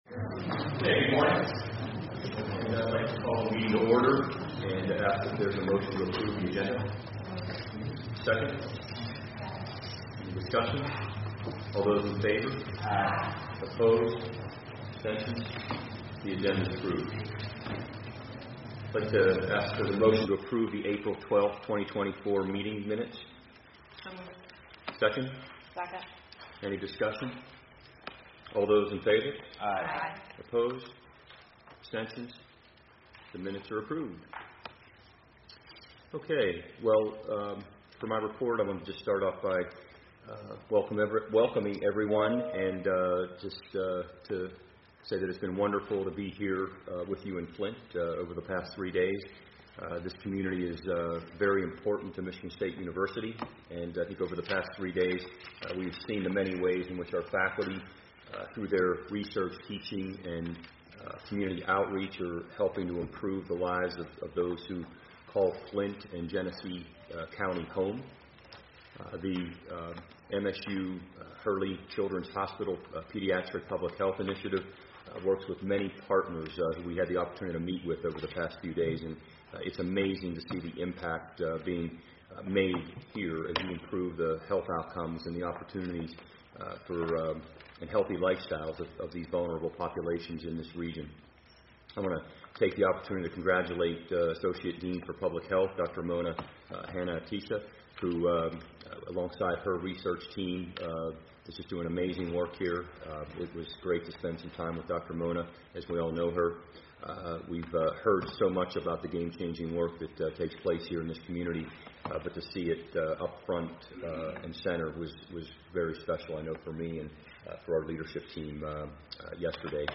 June 28, 2024 Meeting | Board of Trustees | Michigan State University
Where: MSU College of Human Medicine, 200 East First Street, Flint, MI 48502